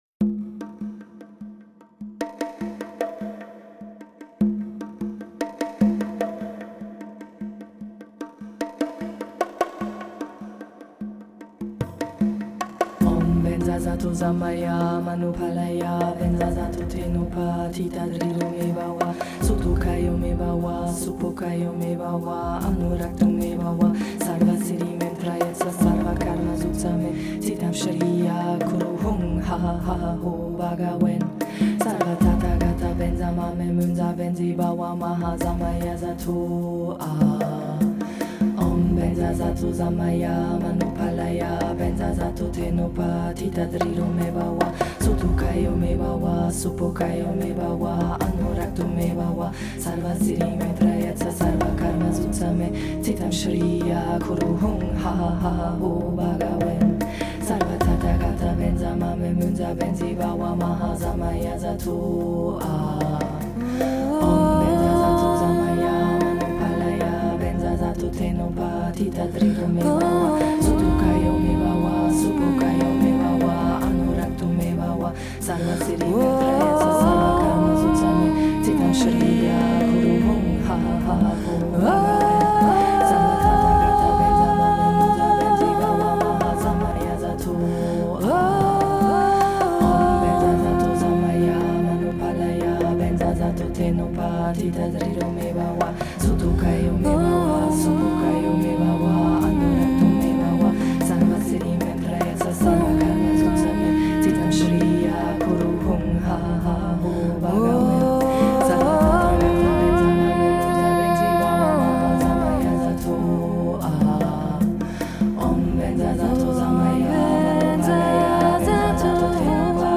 Dorje_Sempa_-_Mantra_100_slogovaya.mp3